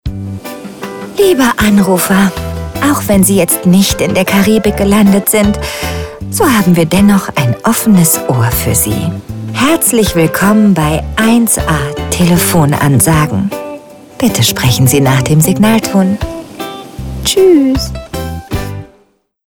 Kreative Telefonansage
Telefonansagen mit echten Stimmen – keine KI !!!
Beispiel 13. mit der deutschen Synchronstimme von Drew Barrymore